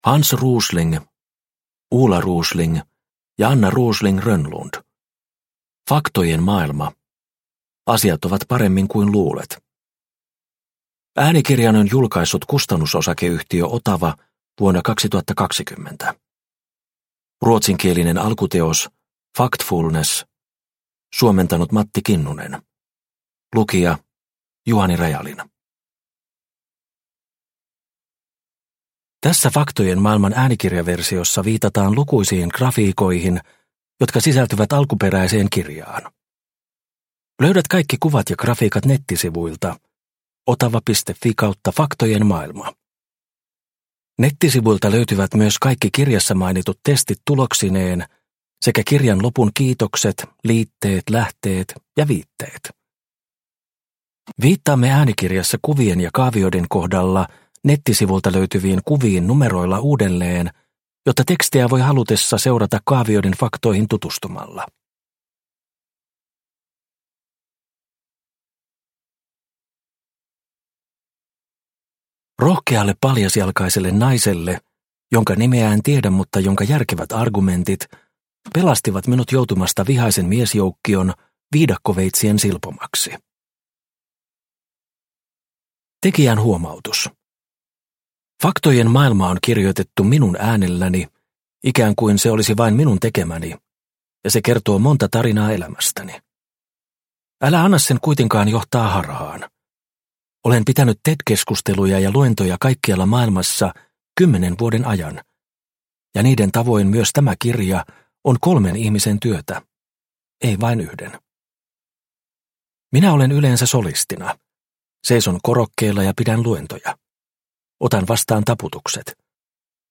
Faktojen maailma – Ljudbok – Laddas ner